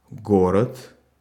Ru-город.ogg.mp3